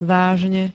vážne [vysl. tvrdé n], -eho str. dopr. poplatok za váženie (napr. pri vagónových zásielkach)
Zvukové nahrávky niektorých slov